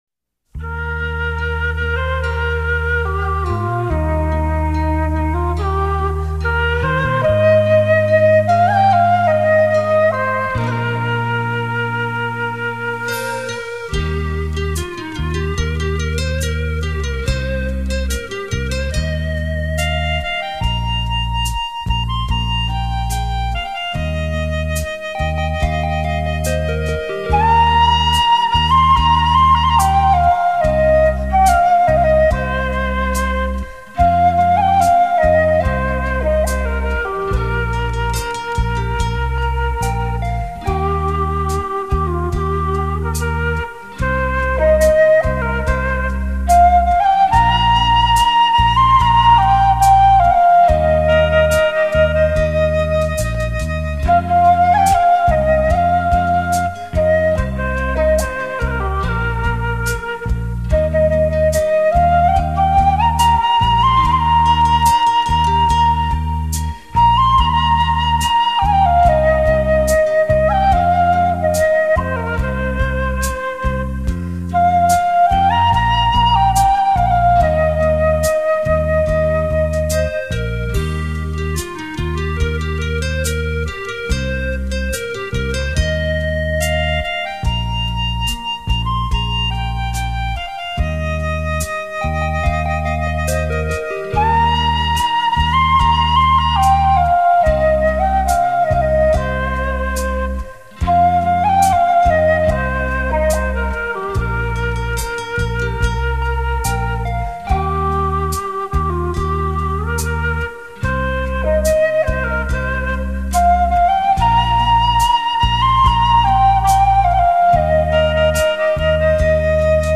★樂壇超炫演繹洞簫電子琴作品，旋律優雅，抒情逸致，曲曲沁心
繞場立體音效 發燒音樂重炫
电子琴伴奏的洞箫演奏韵味不错啊